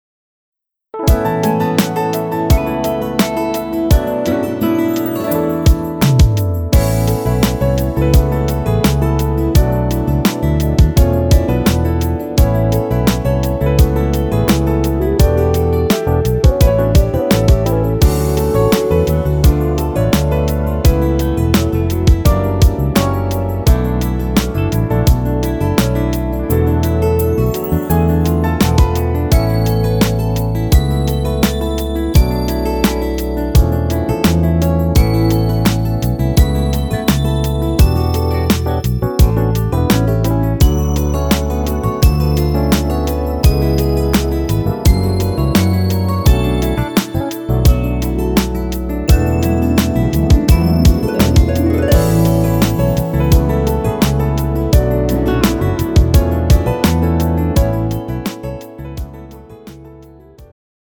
음정 남자-2키
장르 축가 구분 Pro MR